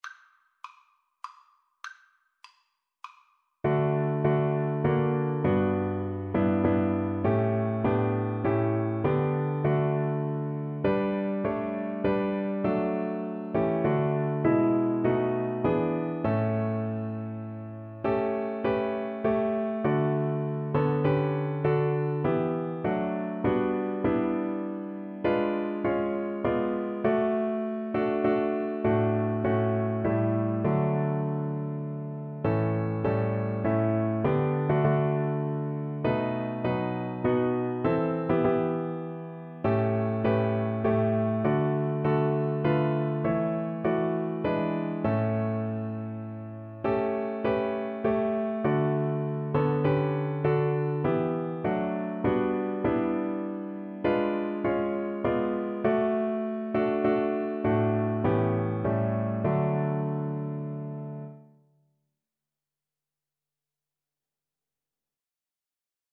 3/4 (View more 3/4 Music)
Classical (View more Classical Saxophone Music)